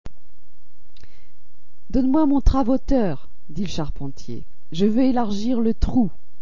Überzeugen Sie sich selber von dem Unterschied mit dem folgenden französischen Mustersatz (travoteur ist ein Phantasiewort):